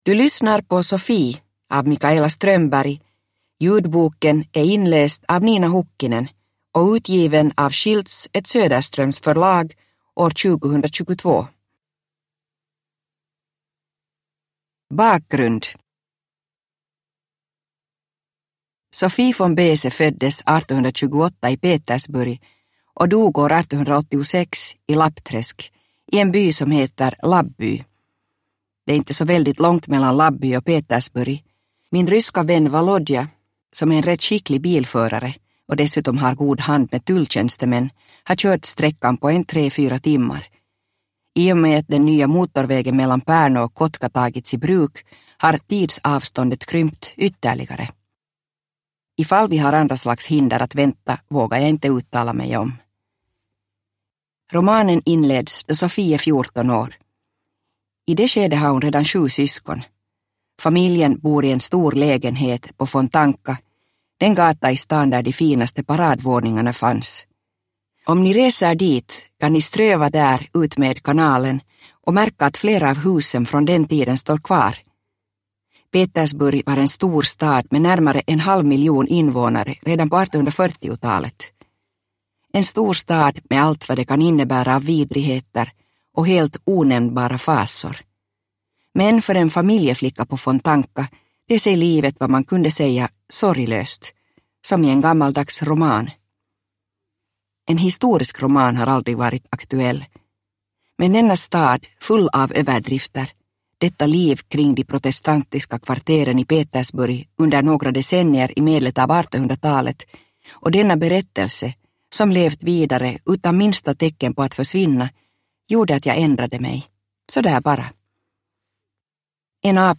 Sophie – Ljudbok – Laddas ner